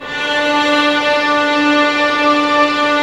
Index of /90_sSampleCDs/Roland - String Master Series/STR_Vlns Bow FX/STR_Vls Sul Pont